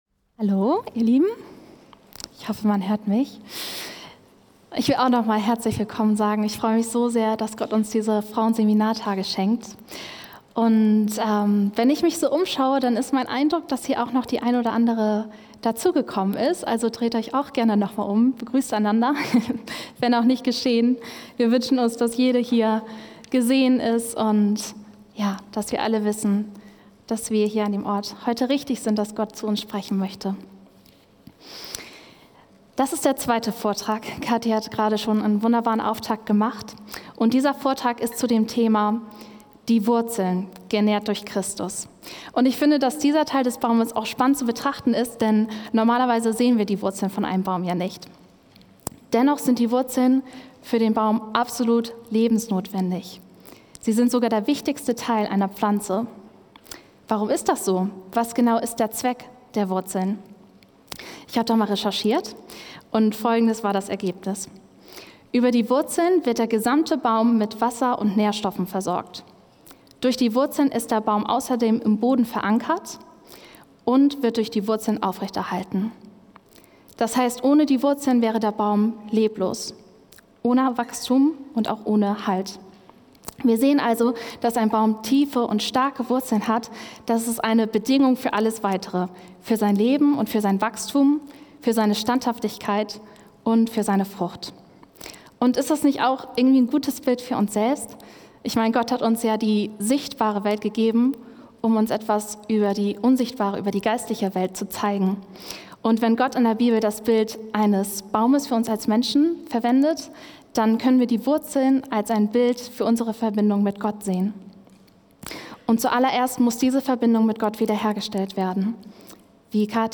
Frauenseminartage 2024 – 2. Vortrag: Die Wurzeln – genährt durch Christus